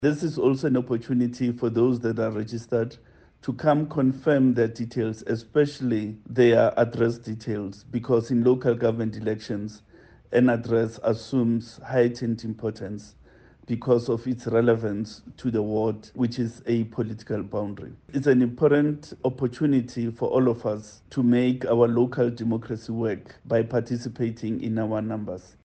He says the registrations would take place under strict Covid-19 protocols.